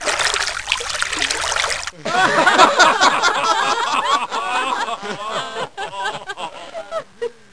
lachen.mp3